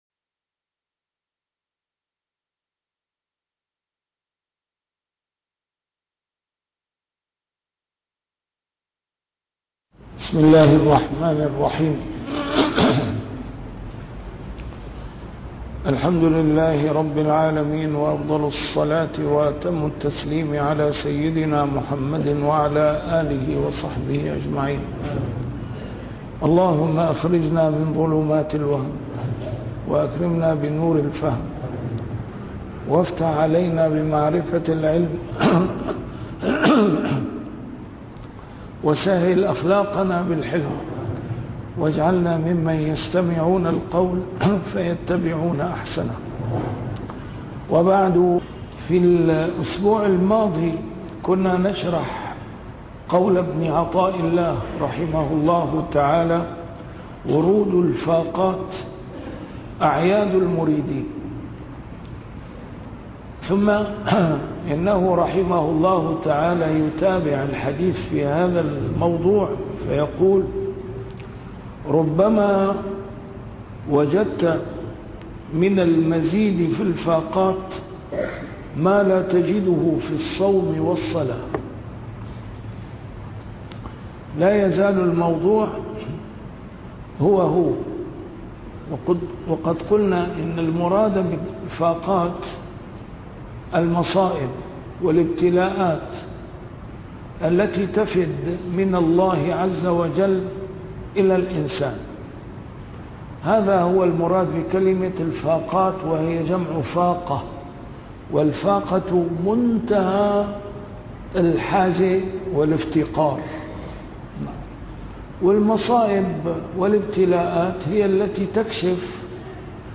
نسيم الشام › A MARTYR SCHOLAR: IMAM MUHAMMAD SAEED RAMADAN AL-BOUTI - الدروس العلمية - شرح الحكم العطائية - الدرس رقم 196 الحكمة رقم 175